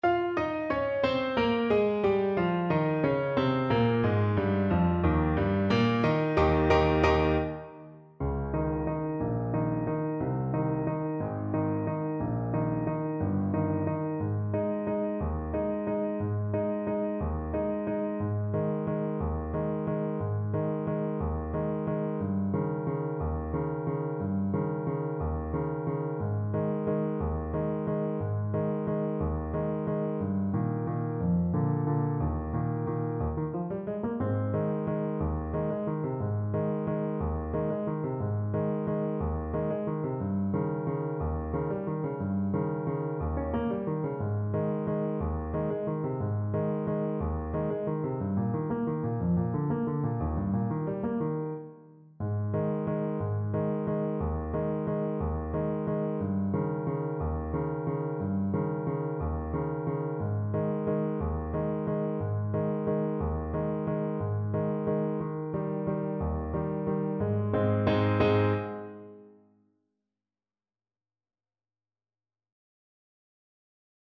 Alto Saxophone
Ab major (Sounding Pitch) F major (Alto Saxophone in Eb) (View more Ab major Music for Saxophone )
. = 60 Tempo di Valse ( . = c. 60)
3/4 (View more 3/4 Music)
F#4-Eb6
Classical (View more Classical Saxophone Music)